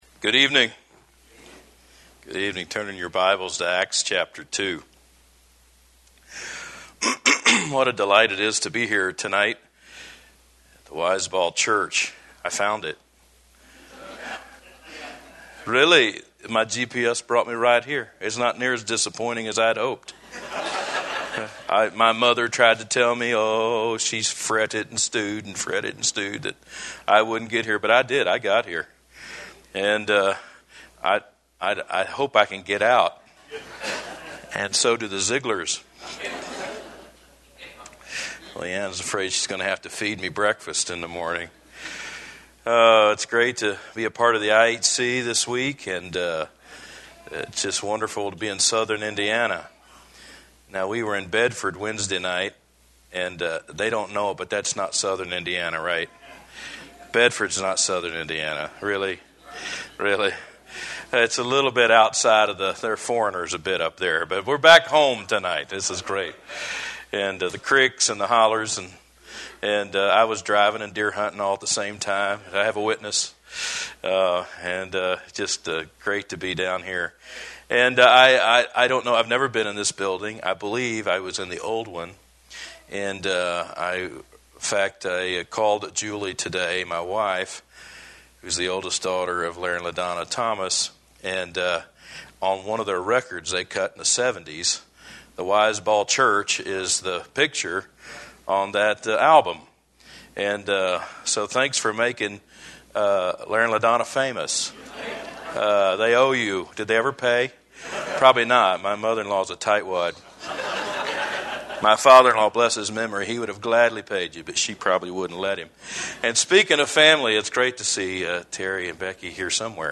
A message